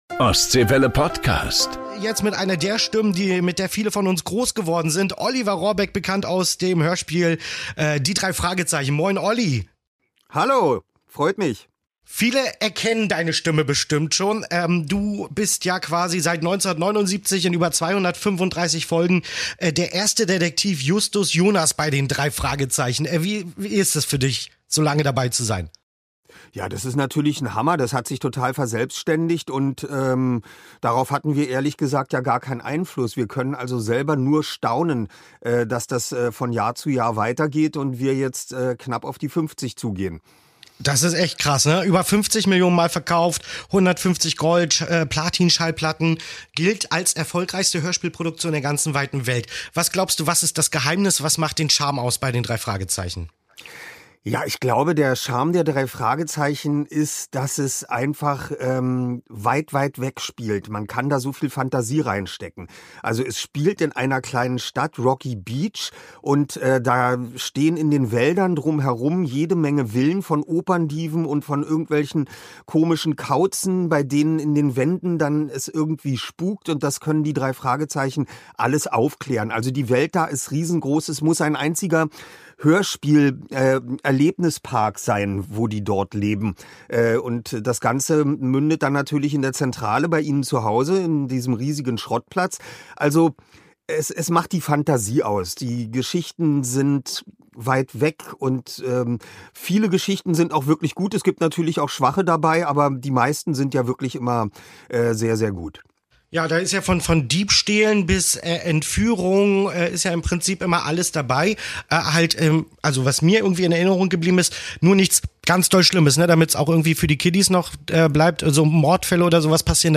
Oliver Rohrbeck ~ "Promis Plaudern Privat" bei Ostseewelle Podcast